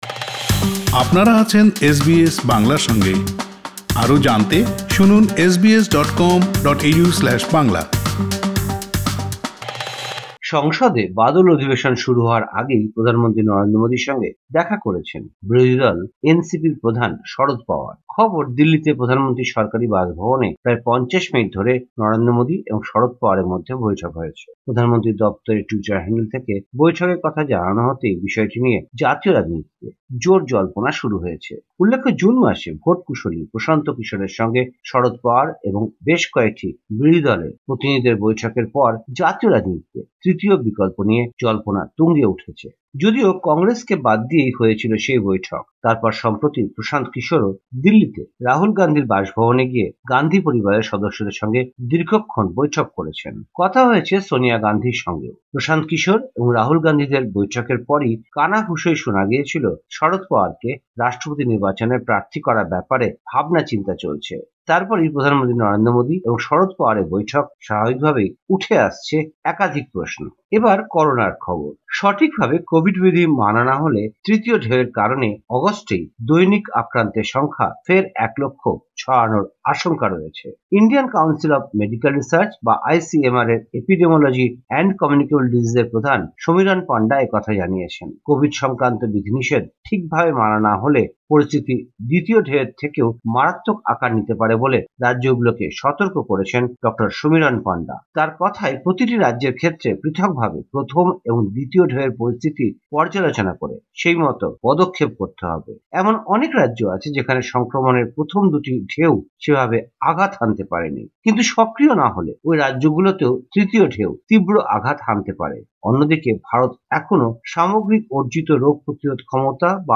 ভারতীয় সংবাদ: ১৯ জুলাই ২০২১